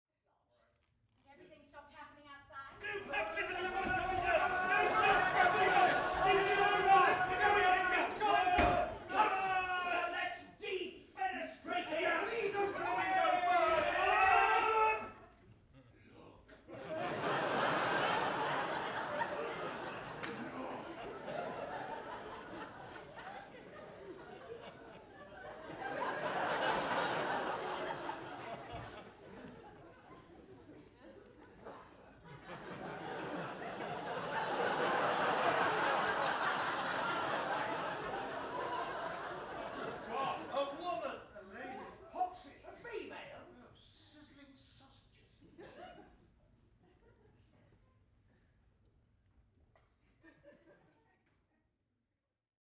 Laughter 3
Some recordings made during a performance of a comedy in London's Glittering West-End! The microphone was mounted on the front of the first circle and there is a some sound from the stage to give location. The theatre is a traditional horseshoe shape, so the audience noise should wrap round about 180 degrees and there should be sounds from both above and below.
Ambisonic
Ambisonic order: F (4 ch) 1st order 3D
Microphone name: Soundfield ST250
Array type: Tetrahedral
Capsule type: Sub-cardioid
jl_laffs3_DTS.wav